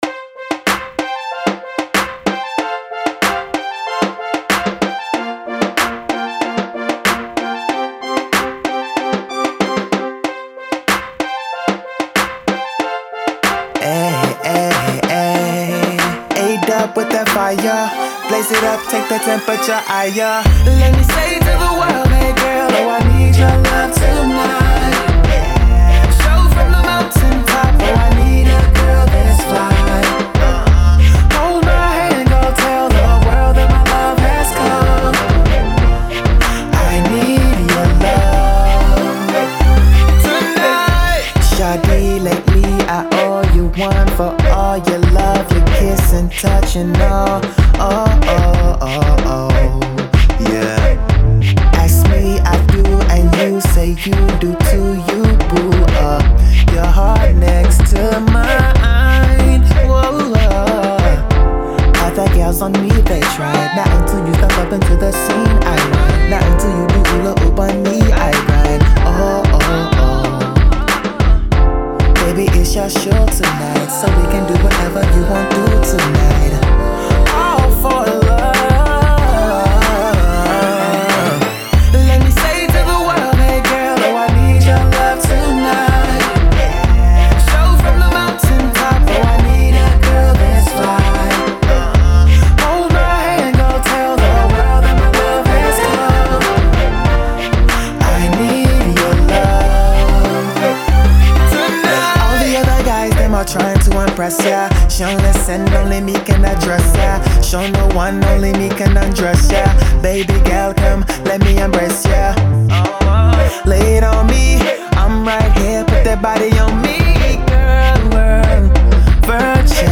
is a 21 year old Nigerian R&B/Pop singer based in the US.
catchy new single
soft vocals